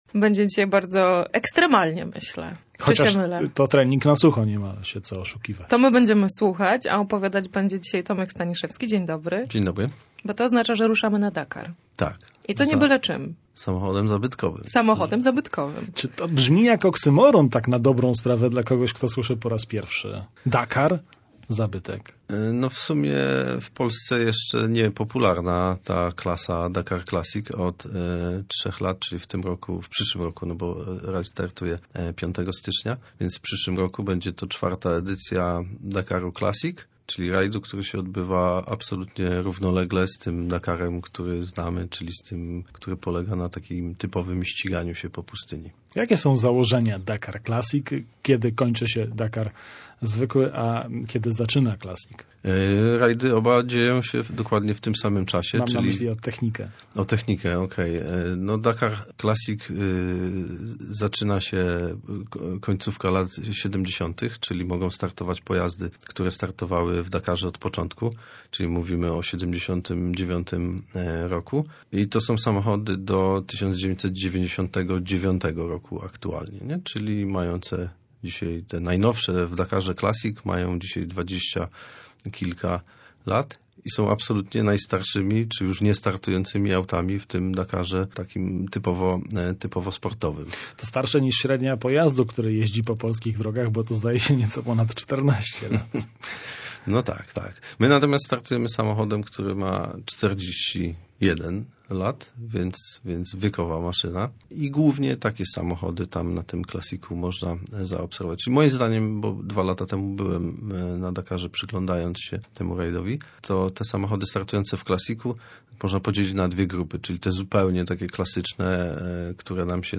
W audycji „Autocooltura” rozmowa